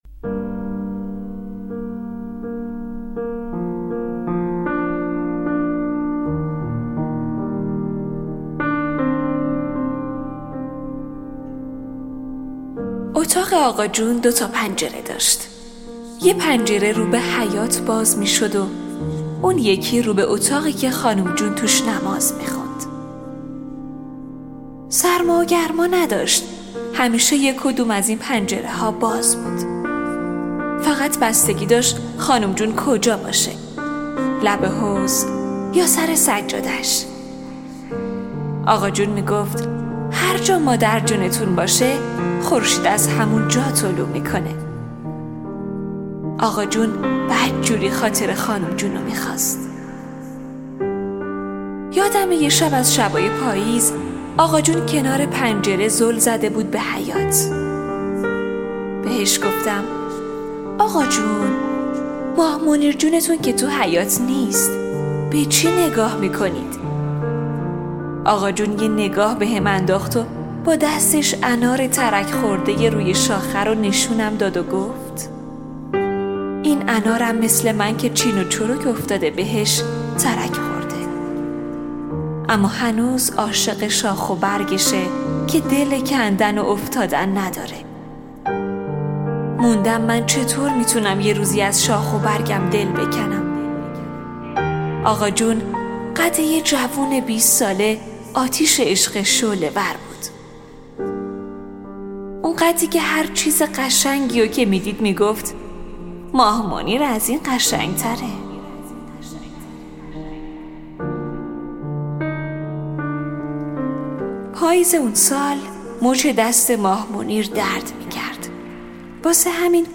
میکس و مسترینگ